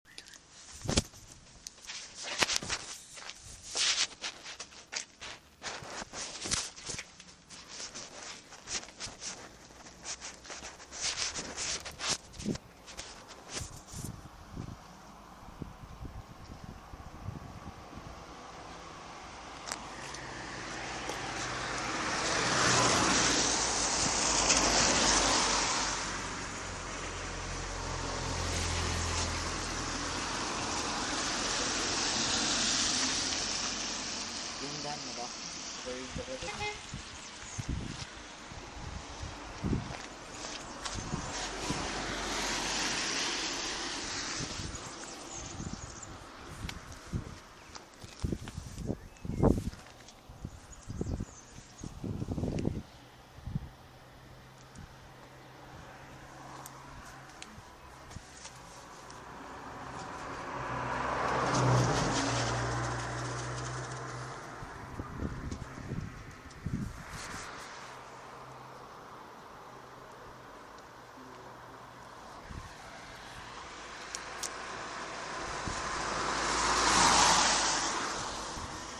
oiseaux et voitures